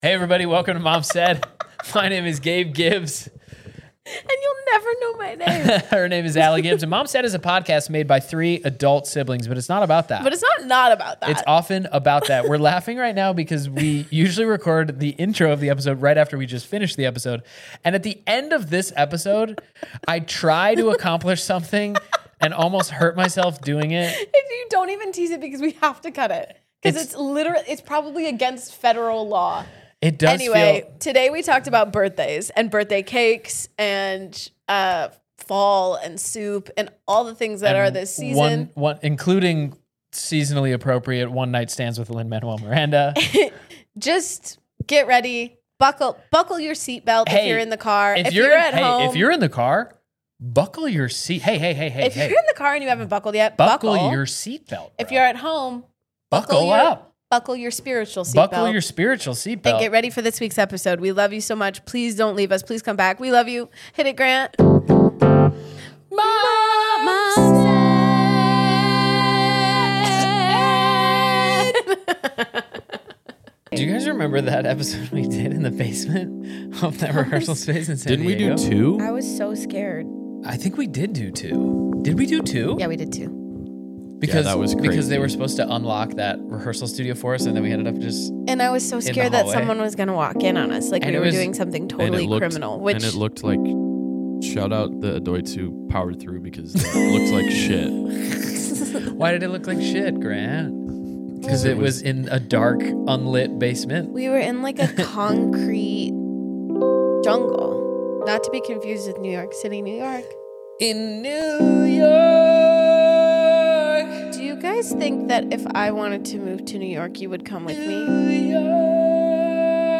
This week the siblings talk about gifts, cake, and a one night stand with Lin Manuel Miranda.